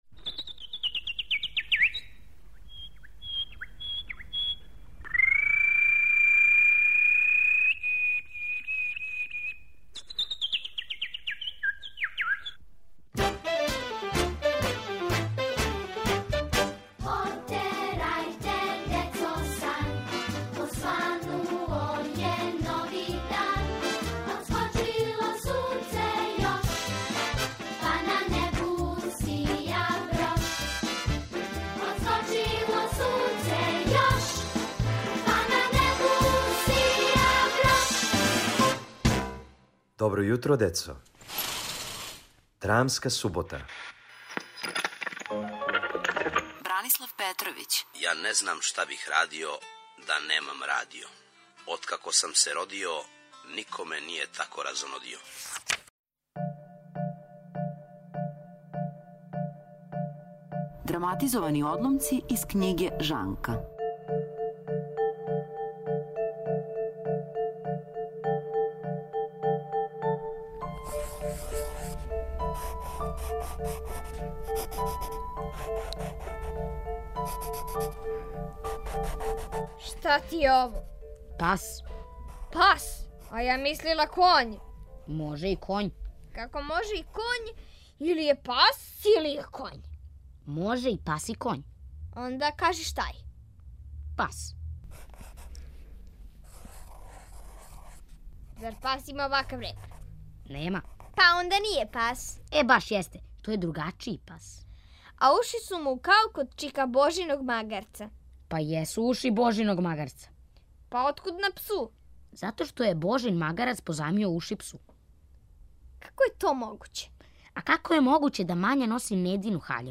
Слушате духовите, драмске одломке из књиге "Жанка" Бране Петровића.